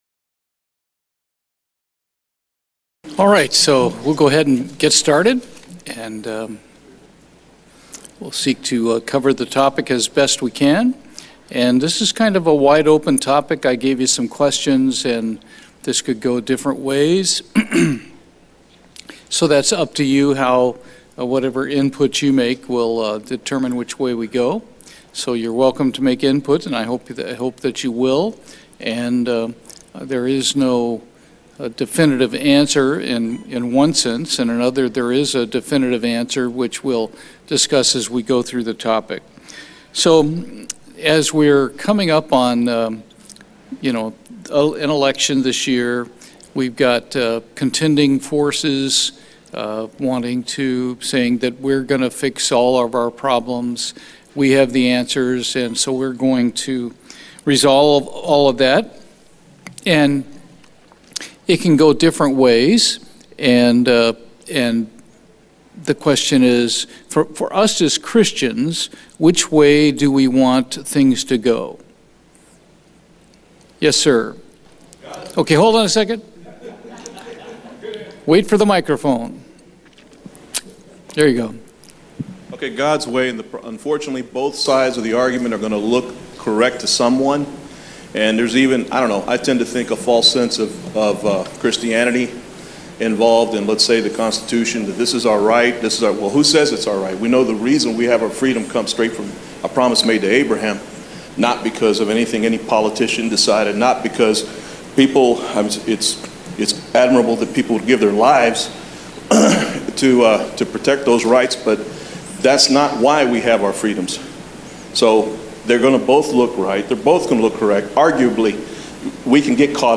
Bible Study, How will American be Made Great Again?
Given in Houston, TX